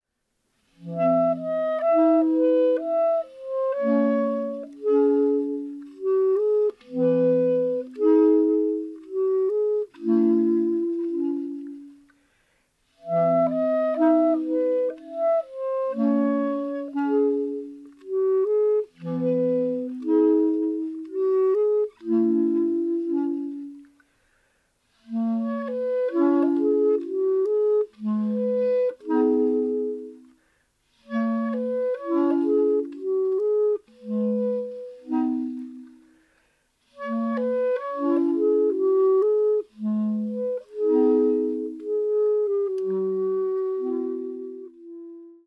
クラリネットだけでため息のような音楽を奏でる